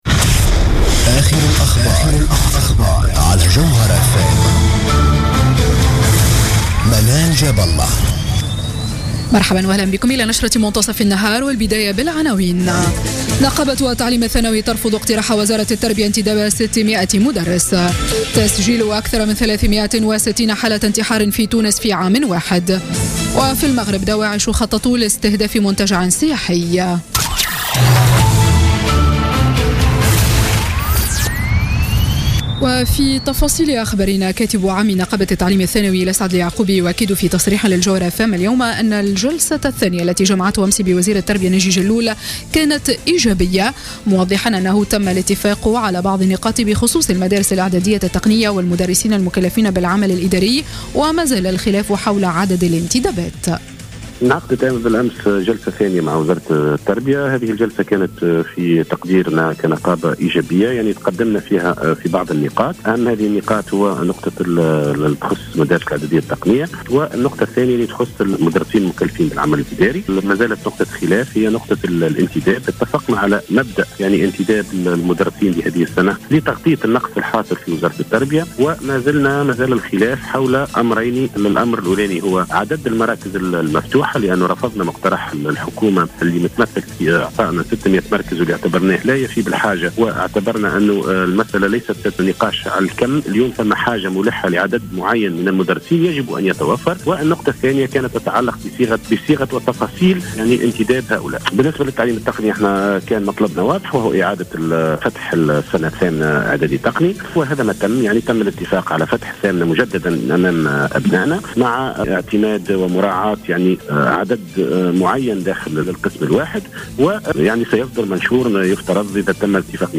نشرة أخبار منتصف النهار ليوم السبت 10 سبتمبر 2016